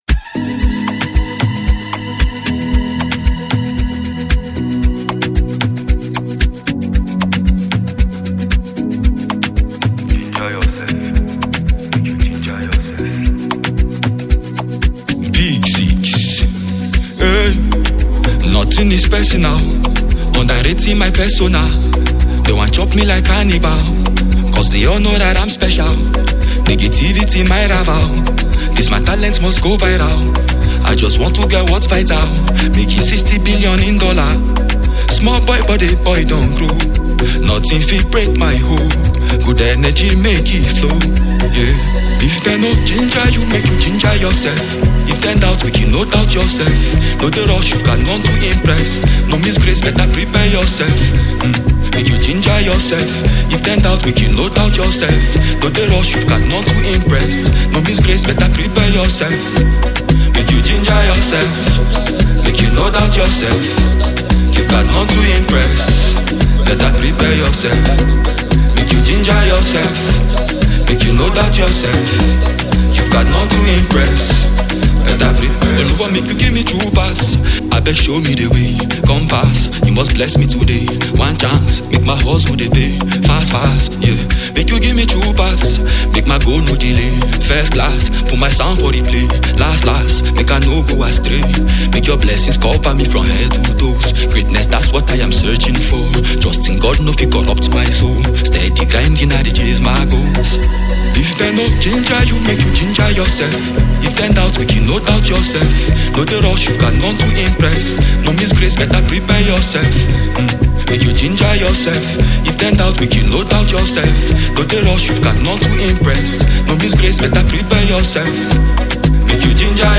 catchy vibes and uplifting energy
It’s a dope track with mad energy and positive vibes.